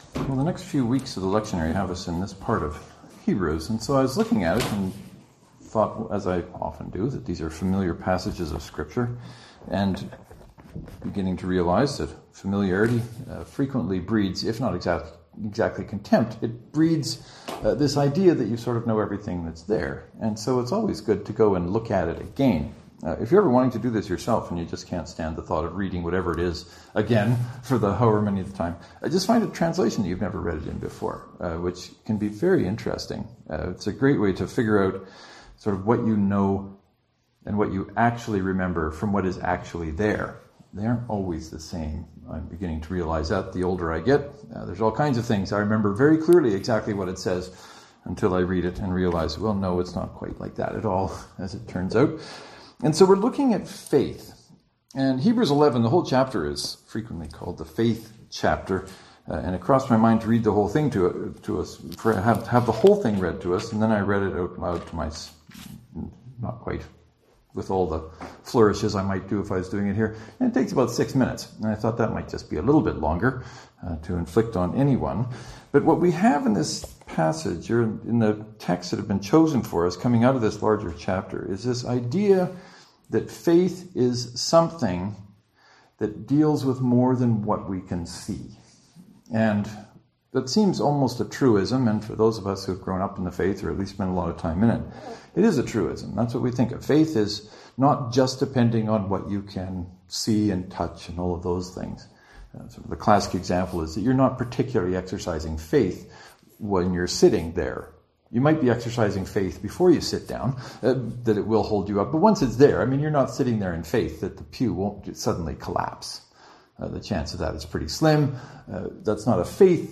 “Faith” Knox Presbyterian (to download, right click and select “Save Link As .